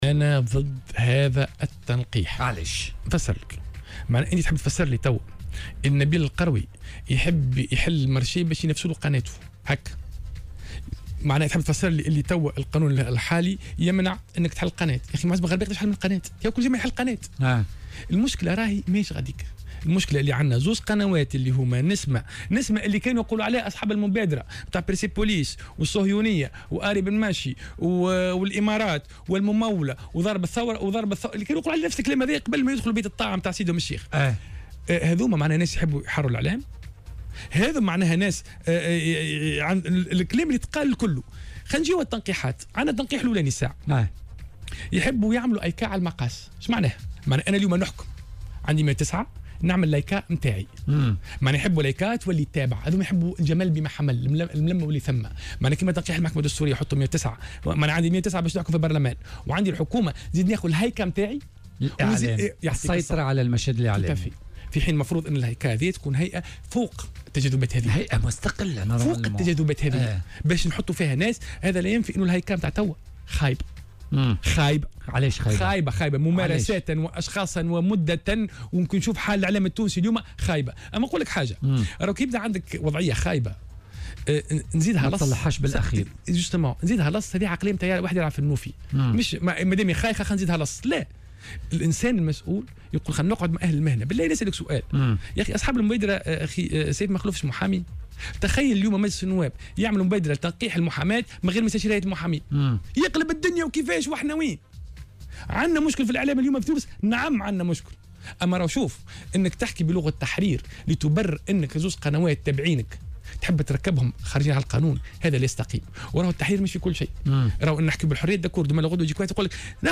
وأضاف في مداخلة له اليوم في برنامج "بوليتيكا" أن هذا المشروع يهدف إلى إحداث هيئة عليا للاتصال السمعي البصري "هايكا" على المقاس والسيطرة على الإعلام، وفق قوله.